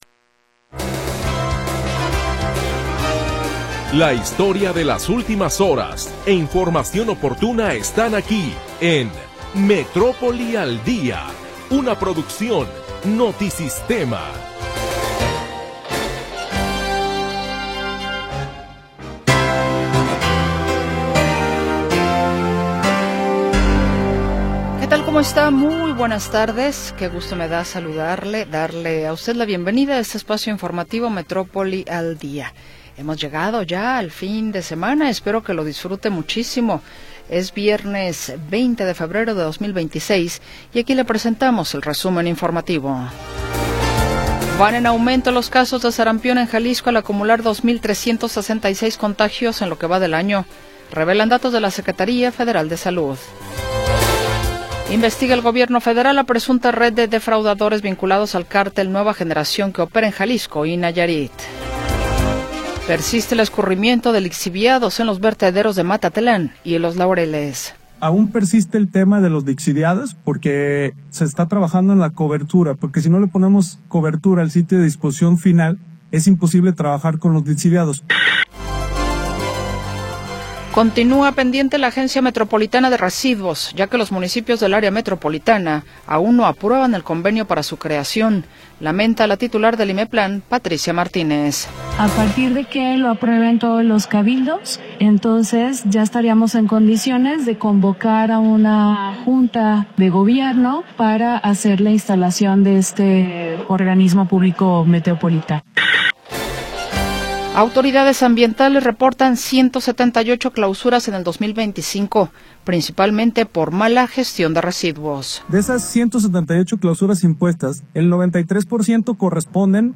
Primera hora del programa transmitido el 20 de Febrero de 2026.